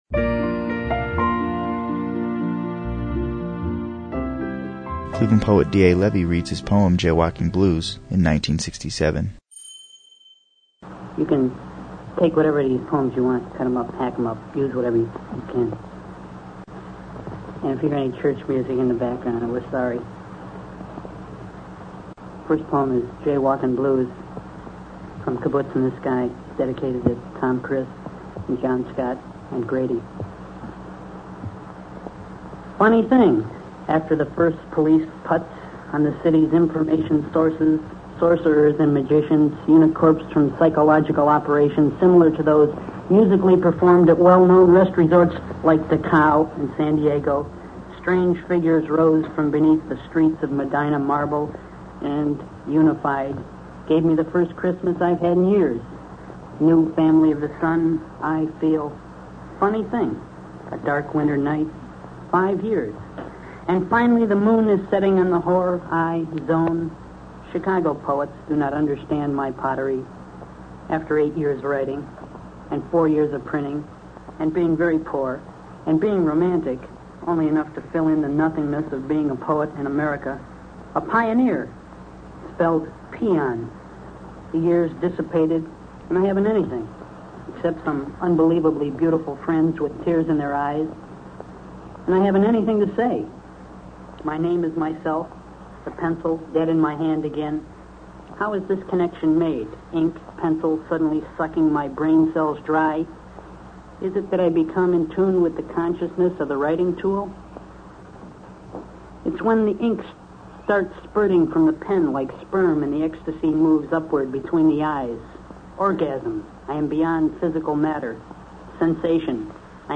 reads his poem